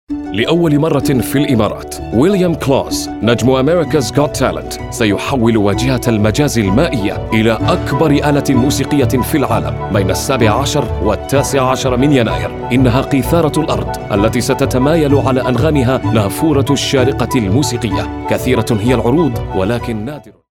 Arabic voiceover. Dubai voiceover